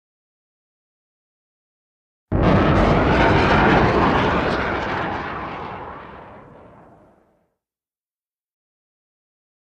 WEAPONS - VARIOUS SURFACE TO AIR MISSILE: EXT: Launch and long rocket trail fade, distant.